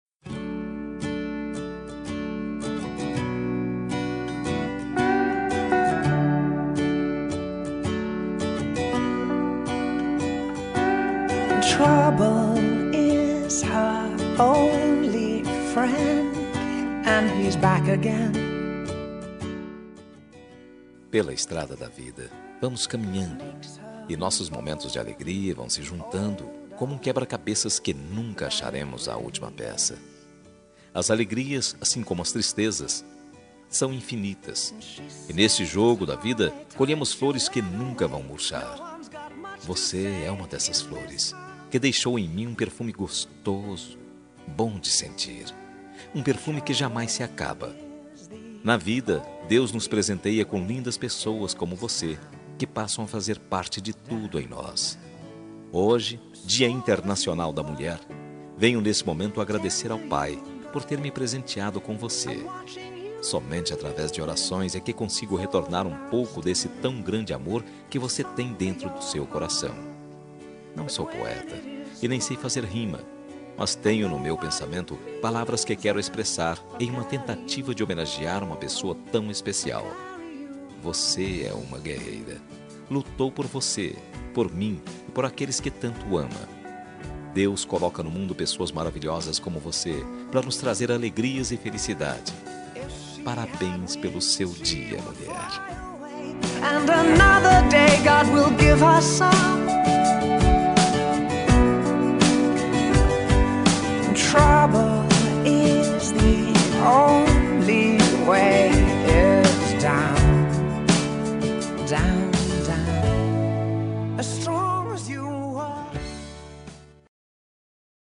Dia das Mulheres Para Namorada – Voz Masculina – Cód: 53050